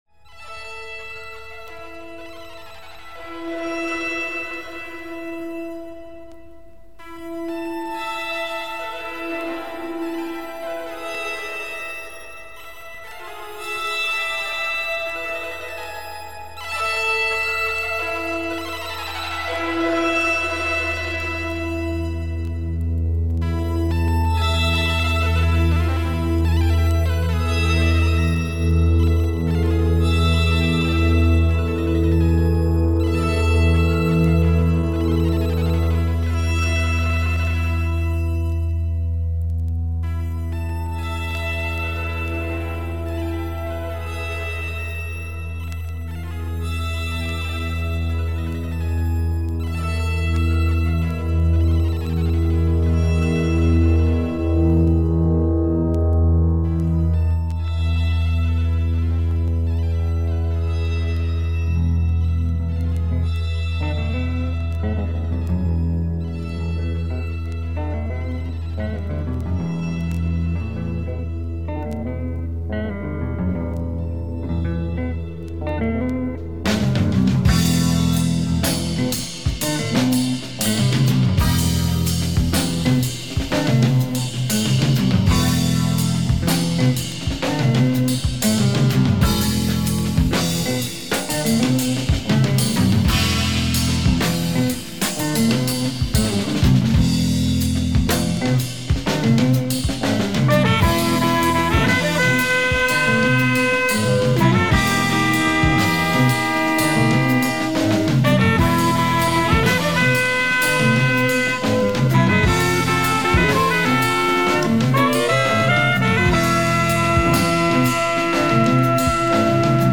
The third album by this underground German kraut jazz band.